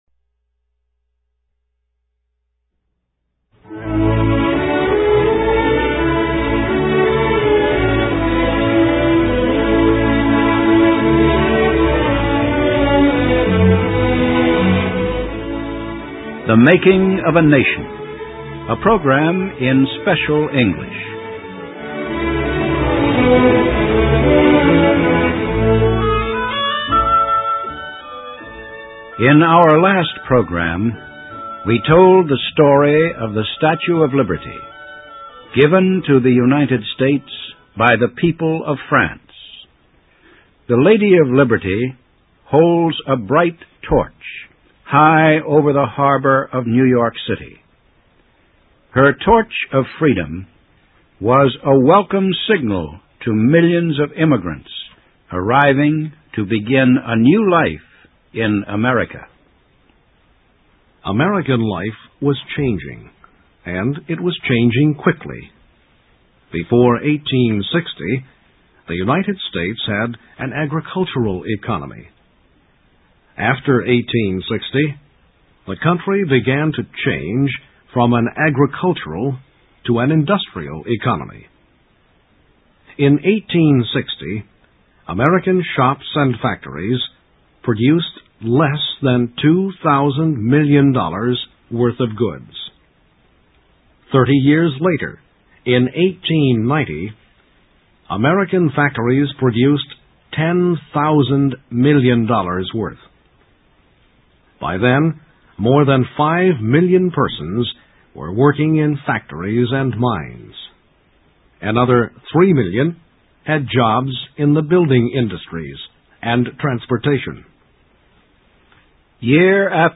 THE MAKING OF A NATION – a program in Special English by the Voice of America.